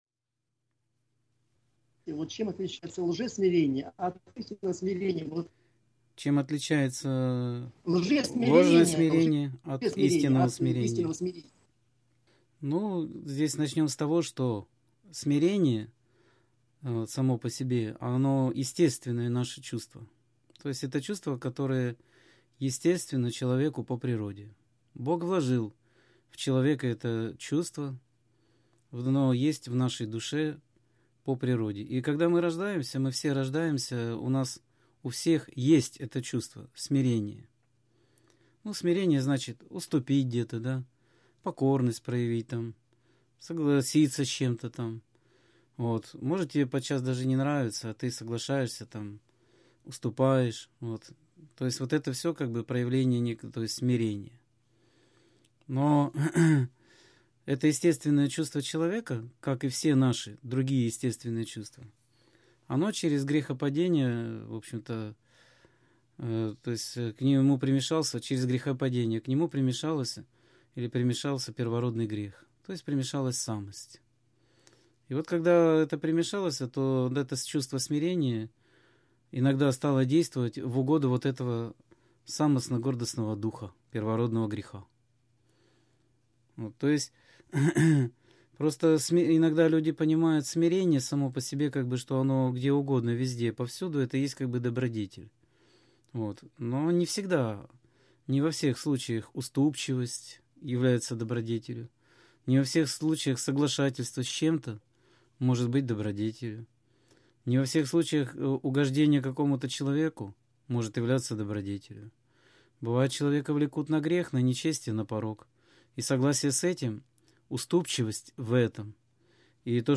Скайп-беседа 27.02.2016 — ХРИСТИАНСКАЯ ЦЕРКОВЬ